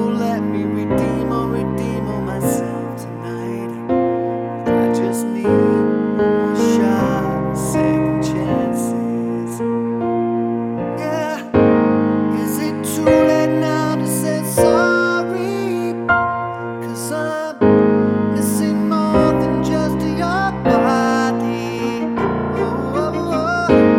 Multiplex Lead Version